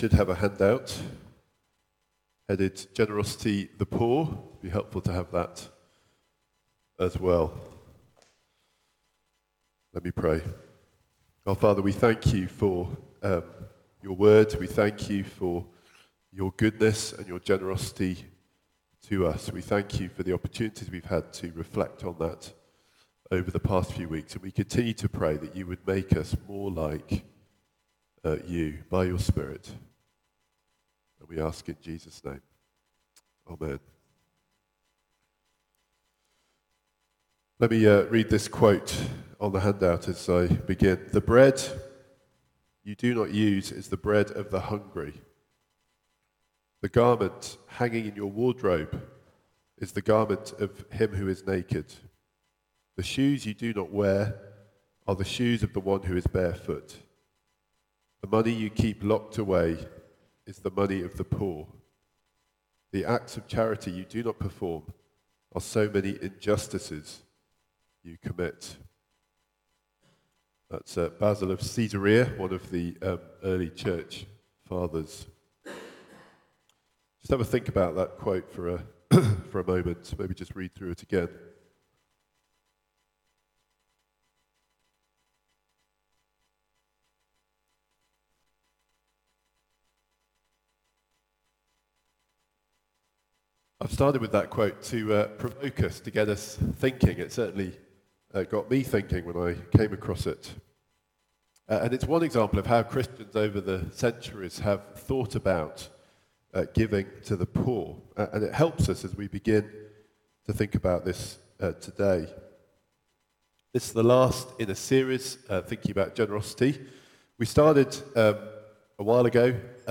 Media Library The Sunday Sermons are generally recorded each week at St Mark's Community Church.
Theme: Generosity 5: The Poor Sermon